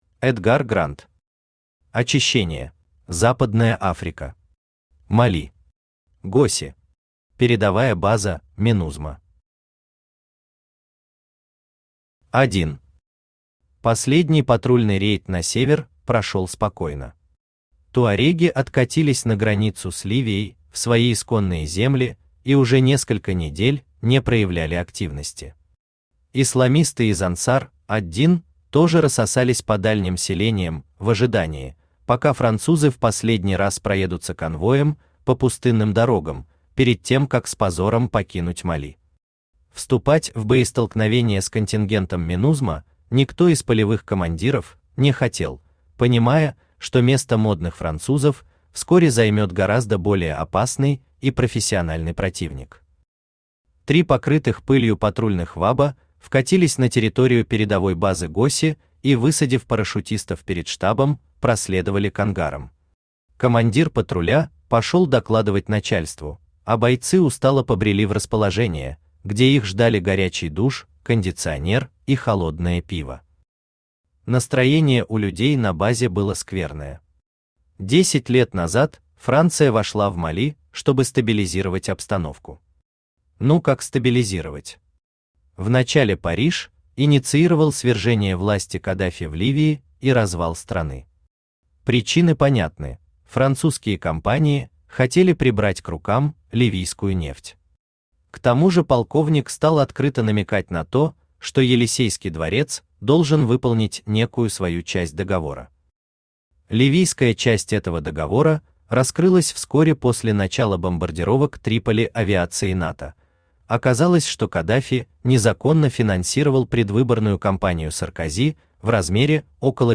Аудиокнига Очищение | Библиотека аудиокниг
Aудиокнига Очищение Автор Эдгар Грант Читает аудиокнигу Авточтец ЛитРес.